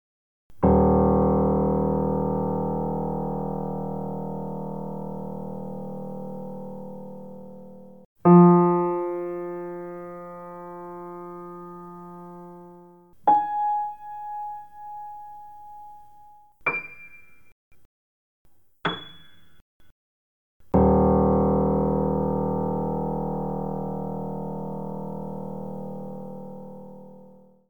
Steinway-zemi-ir-auksti.mp3